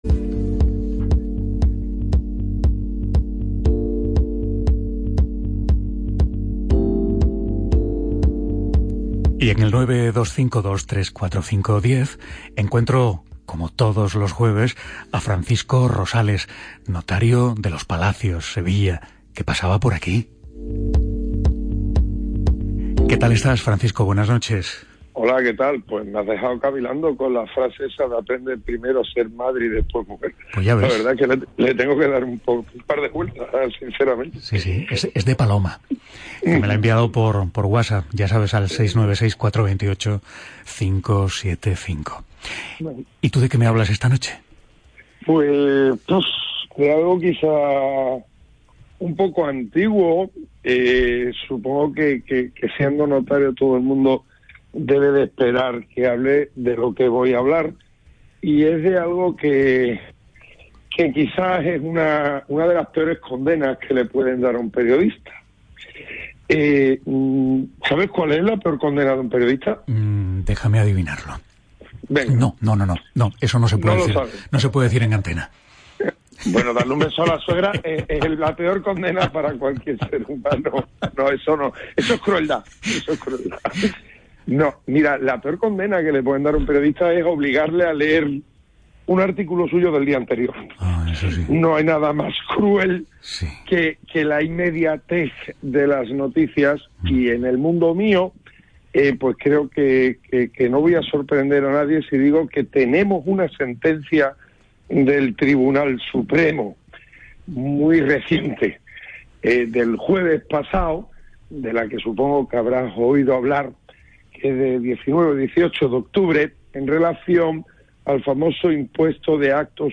Comparto mi intervención en radio CLM sobre la reciente sentencia del Tribunal Supremo sobre quien paga el impuesto de actos jurídicos documentados en las hipotecas